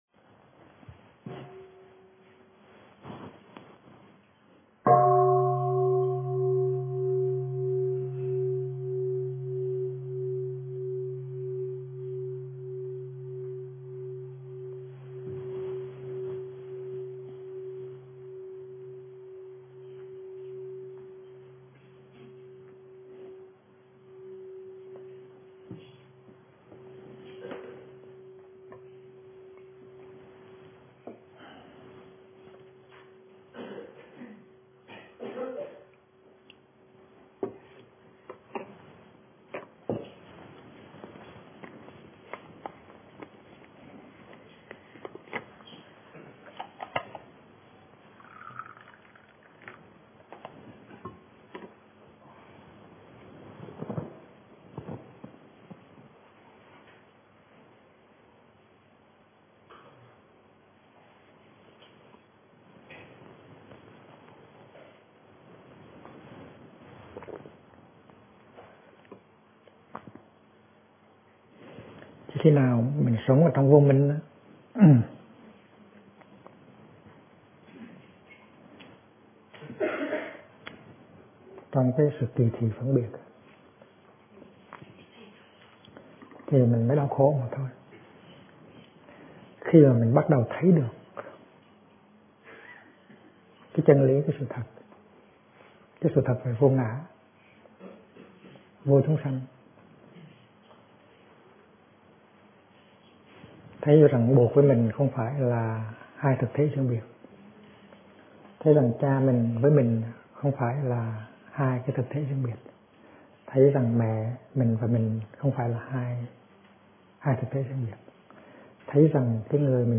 Kinh Giảng Năm Nghiệp Vô Gián - Thích Nhất Hạnh
Năm Nghiệp Vô Gián - Thầy Thích Nhất Hạnh thuyết giảng